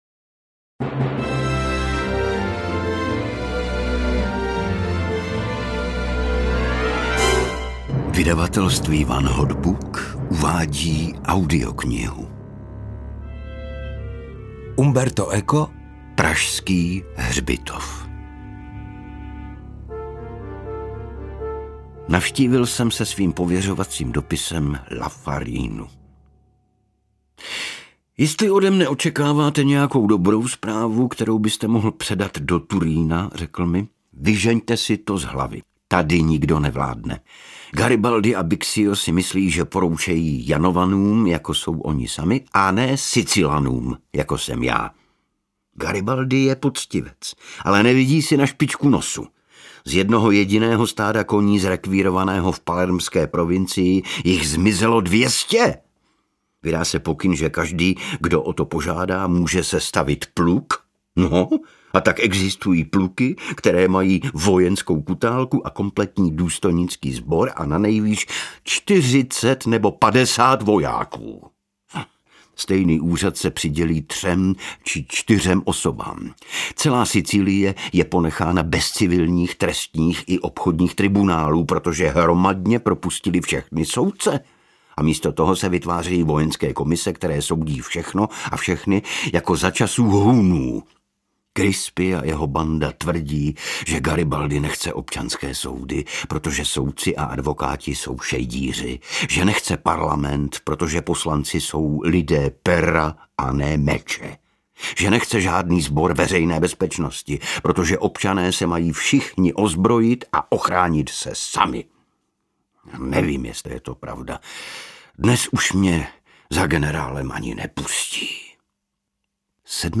Pražský hřbitov audiokniha
Ukázka z knihy
• InterpretIgor Bareš, David Švehlík